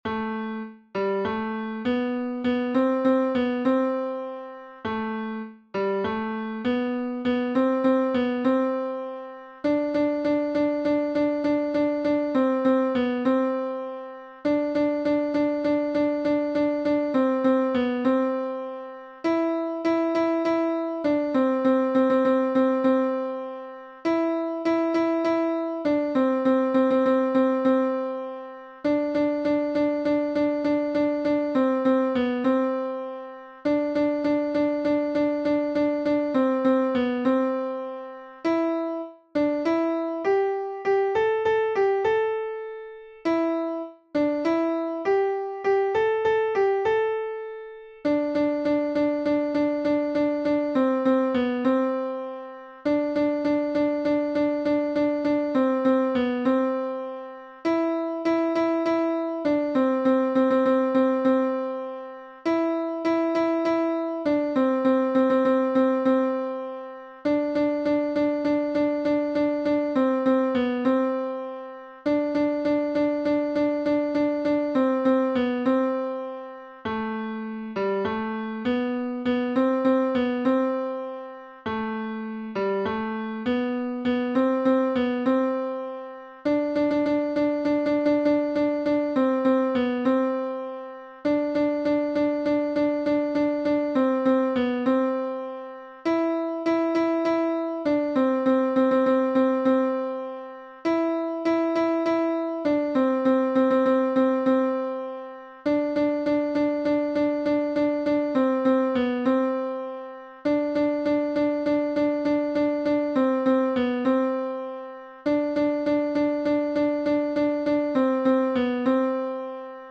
Alto 2 (version piano